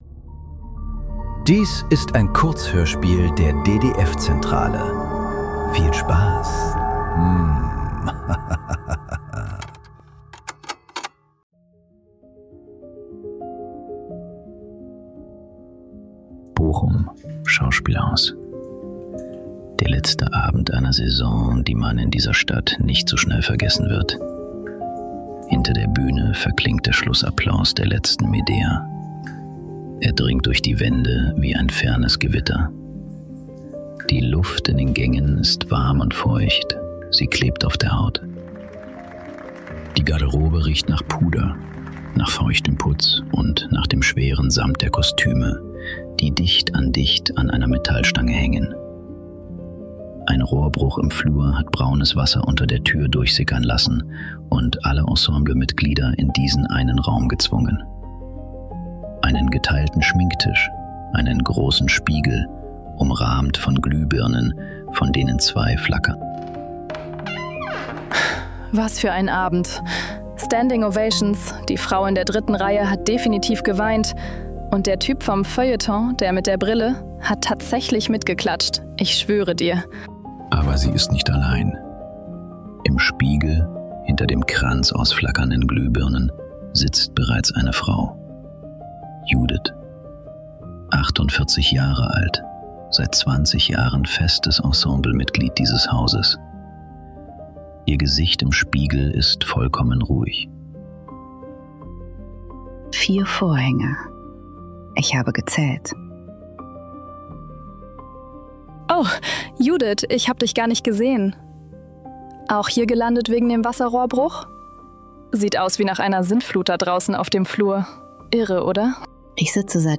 Die Handschrift ~ Nachklang. Kurzhörspiele.